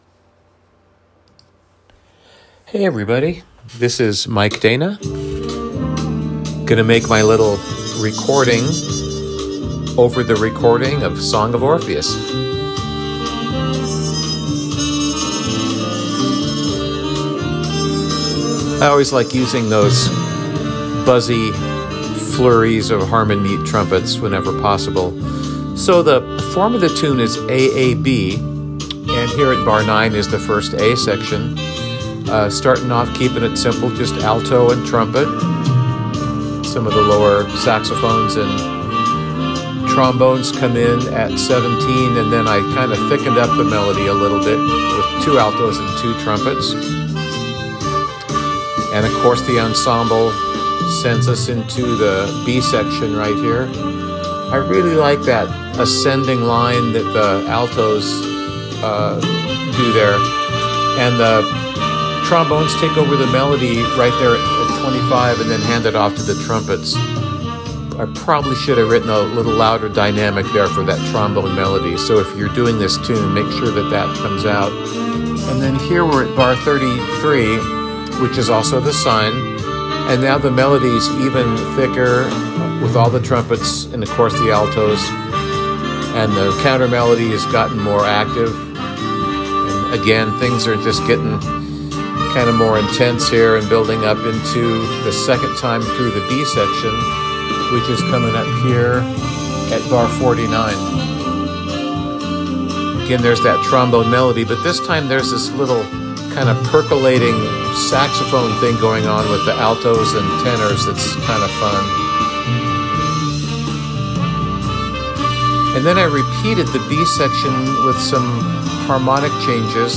Since this part of the site is called Chart Talk, it makes sense to talk (as opposed to write) about what’s going on in the tune.